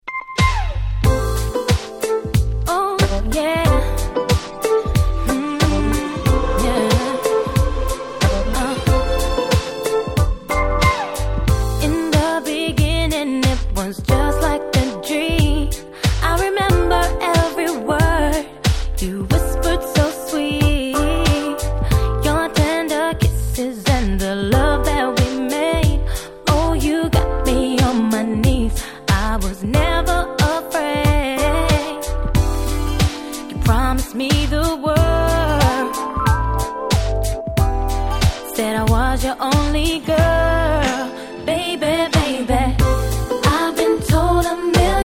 99' Nice UK R&B !!
キラキラ系の切ない美メロトラックと彼女の歌声の相性は完璧！！